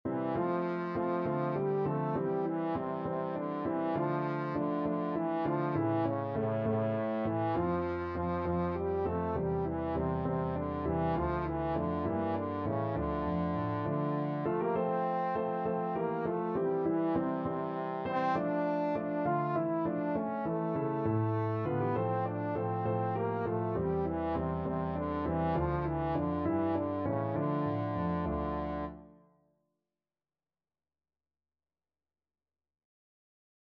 Trombone
F major (Sounding Pitch) (View more F major Music for Trombone )
6/8 (View more 6/8 Music)
Traditional (View more Traditional Trombone Music)